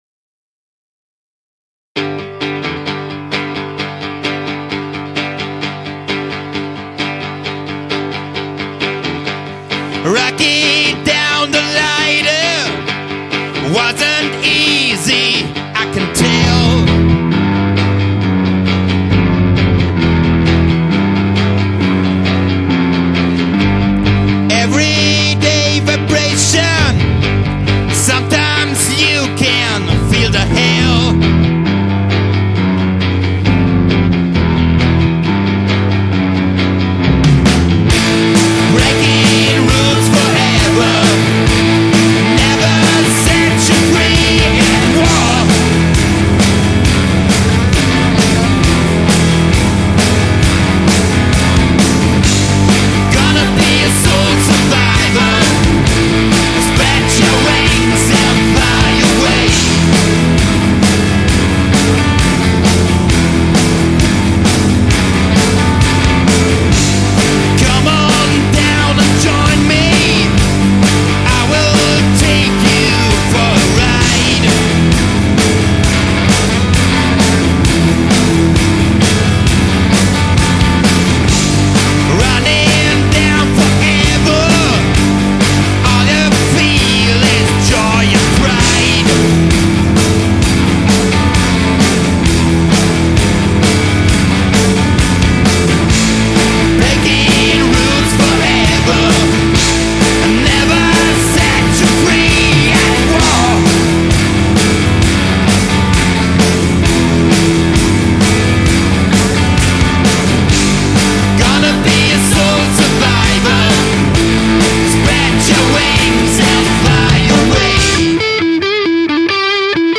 bass
drums
guitar
backingvocals